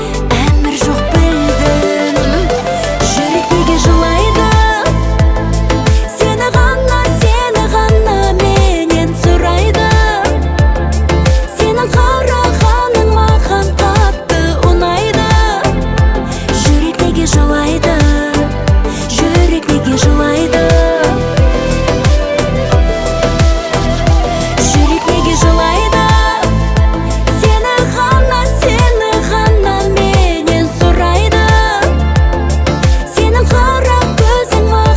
Жанр: Поп музыка / Русский поп / Русские
Pop